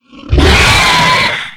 flesh_aggressive_0.ogg